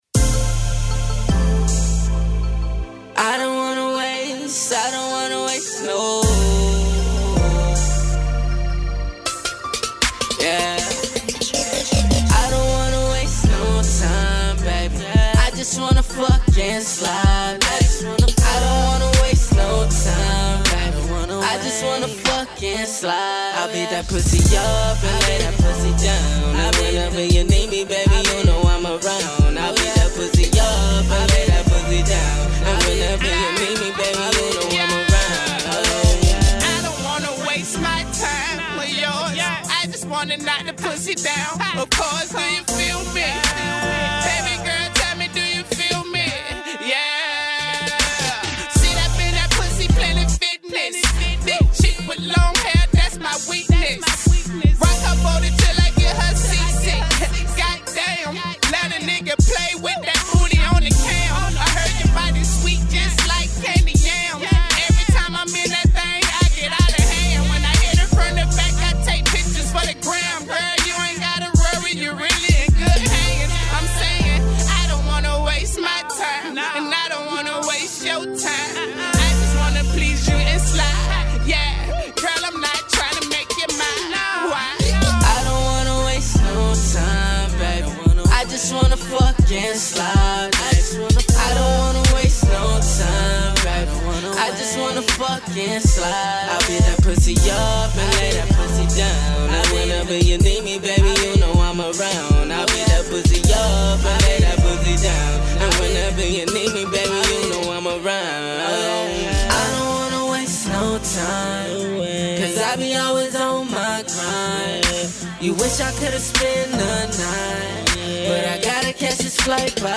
R&B and hip hop style